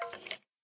minecraft / sounds / mob / skeleton / say2.ogg